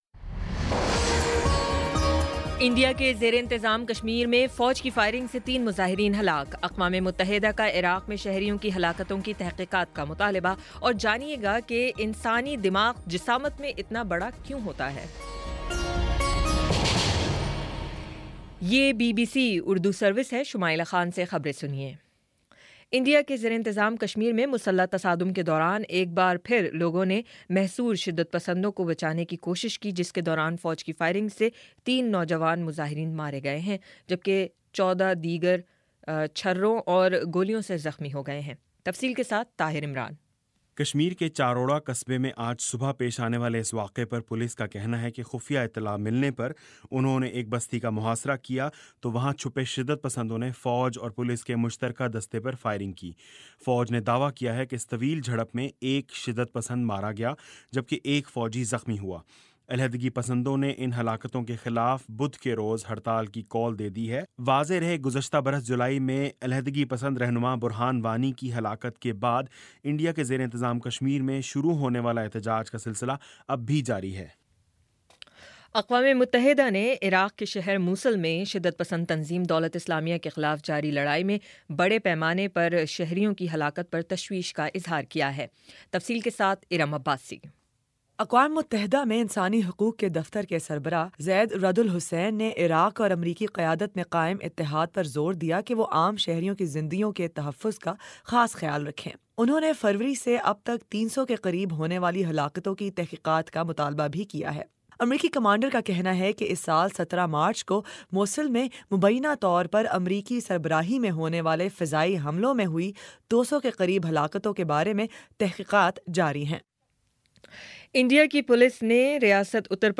مارچ 28 : شام پانچ بجے کا نیوز بُلیٹن